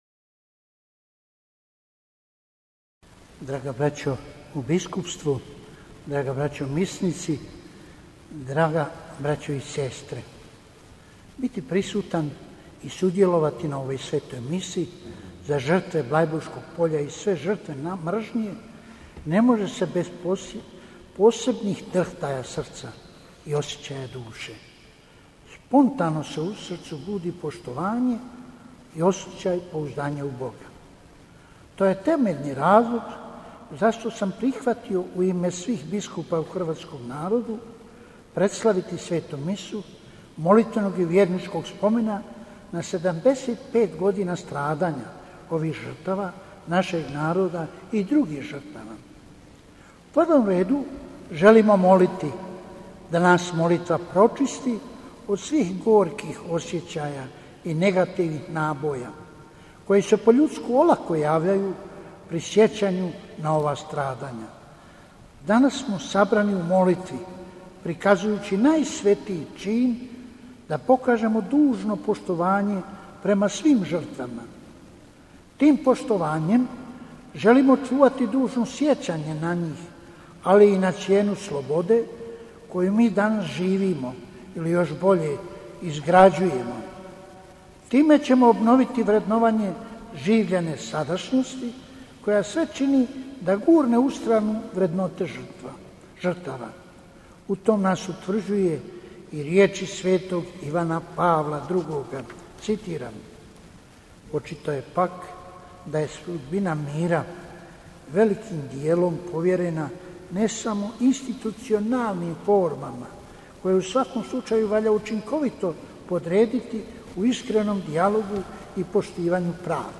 AUDIO: PROPOVIJED KARDINALA VINKA PULJIĆA NA MISI ZA SVE ŽRTVE DRUGOGA SVJETSKOGA RATA TE POSEBNO ZA ŽRTVE BLEIBURŠKE TRAGEDIJE (HR, EN, DE) - BANJOLUČKA BISKUPIJA
Predsjednik Biskupske konferencije Bosne i Hercegovine kardinal Vinko Puljić, nadbiskup metropolit vrhbosanski, u zajedništvu s nadbiskupom vrhbosanskim koadjutorom mons. Tomom Vukšićem i uz koncelebraciju 17 svećenika, 16. svibnja 2020. predvodio je Svetu misu u katedrali Srca Isusova u Sarajevu za sve žrtve Drugoga svjetskoga rata te posebno za žrtve Bliburške tragedije. Zbog aktualnih ograničenja u vremenu pandemije koronavirusa dopušteno je da, osim svećenika, u katedrali bude nazočno još samo 20 osoba.